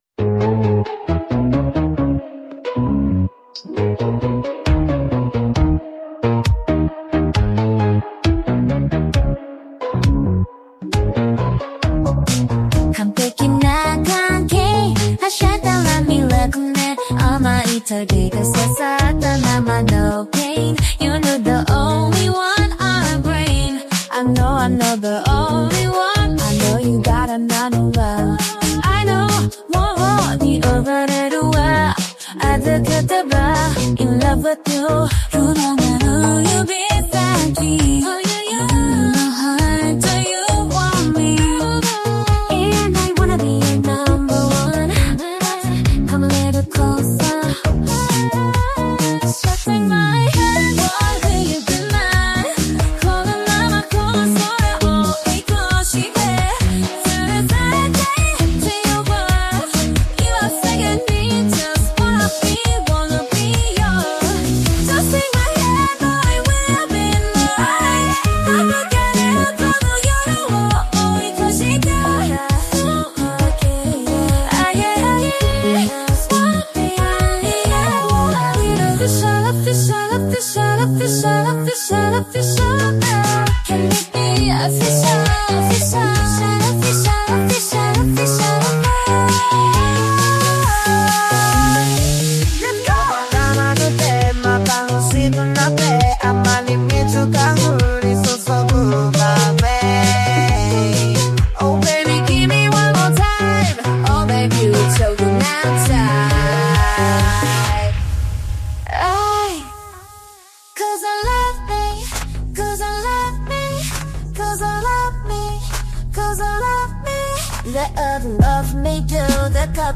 English + Japanese + Korean Code Switching Kpop